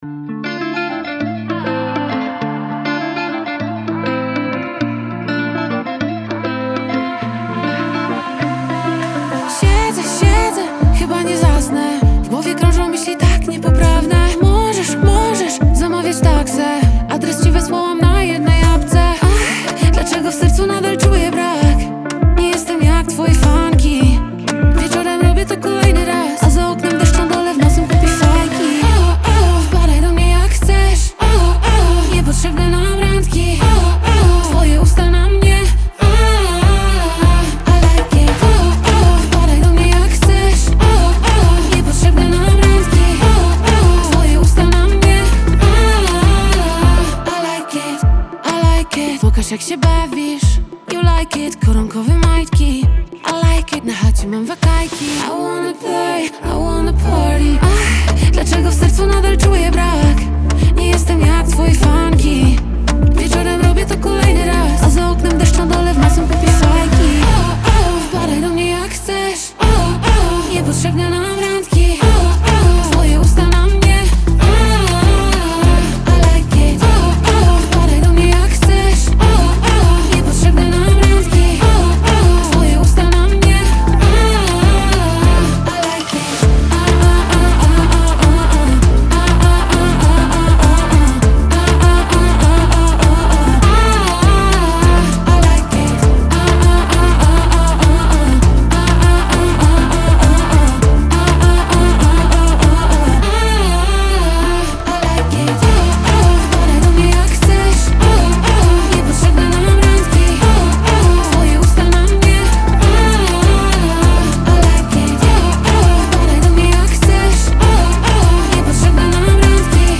Singiel (Radio)